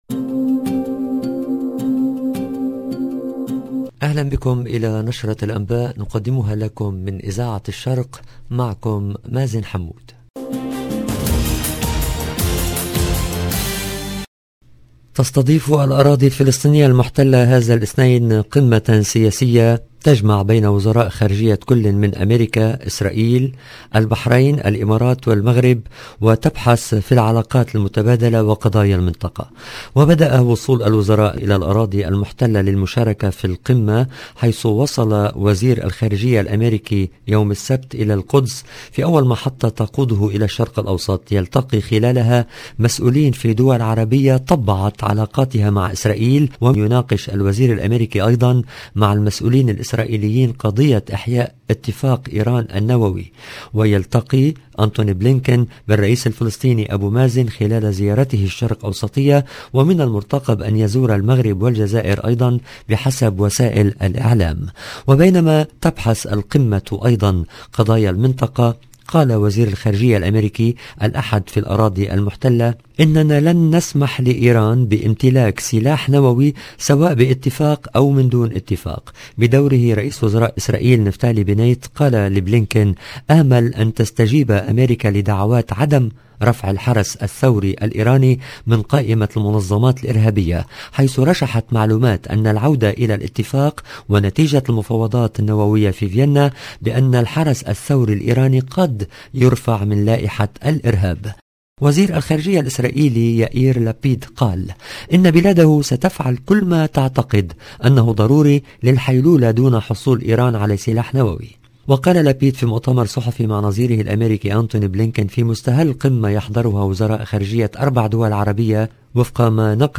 EDITION DU JOURNAL DU SOIR EN LANGUE ARABE DU 27/3/2022